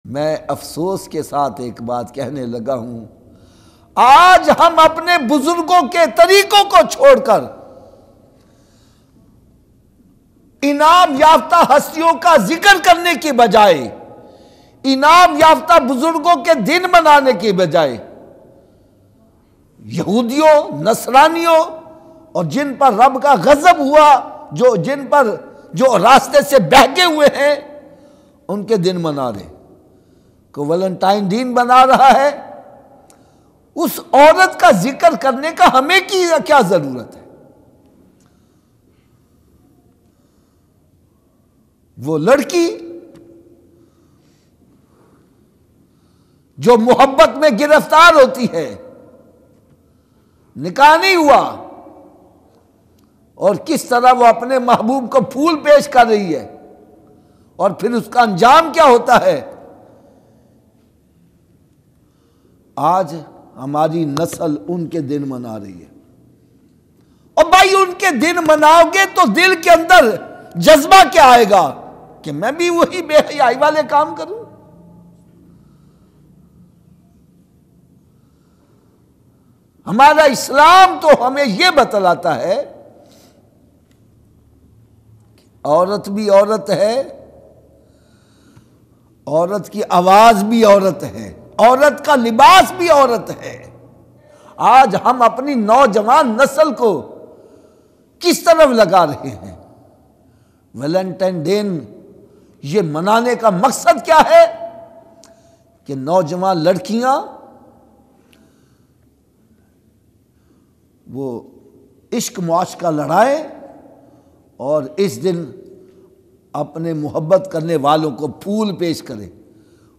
Valentines Special New Bayan